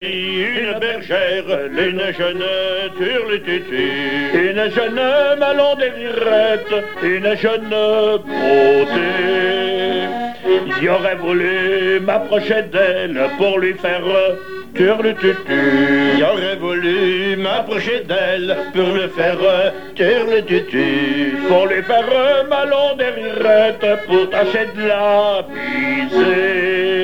Genre laisse
émission La fin de la Rabinaïe sur Alouette